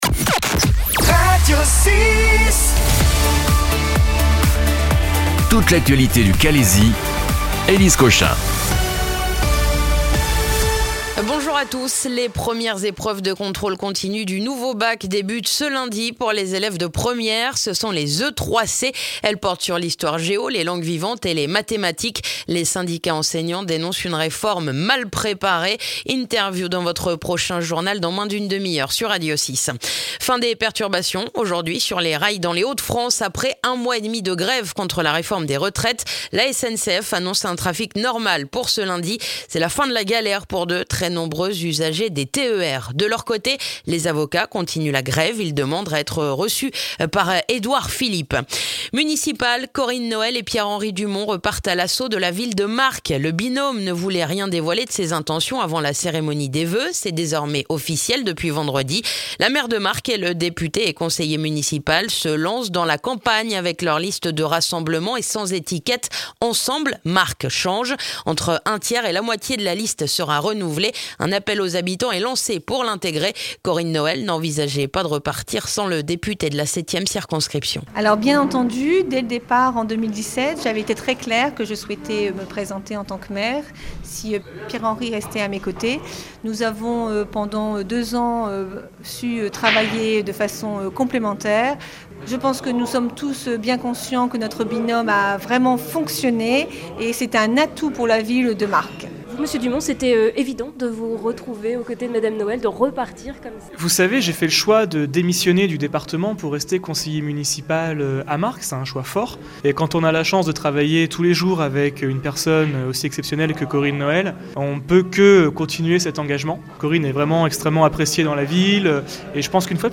Le journal du lundi 20 janvier dans le calaisis